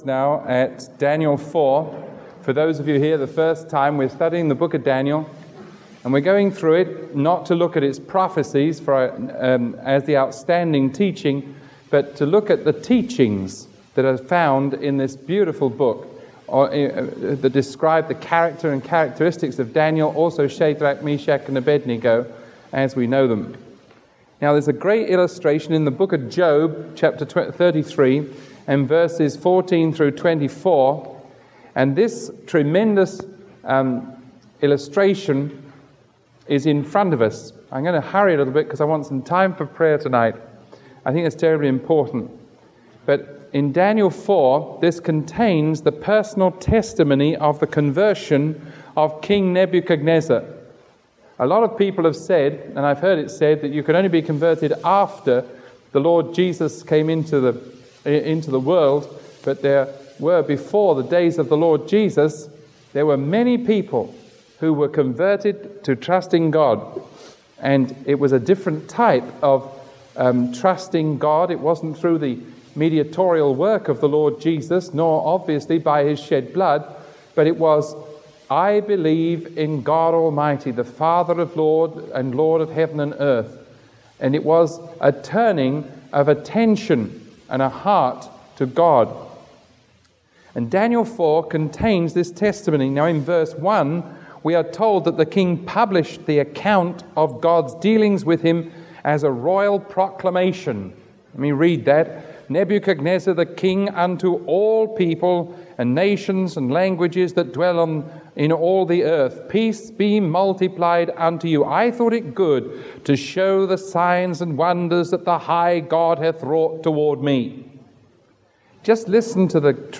Sermon 0474A recorded on January 6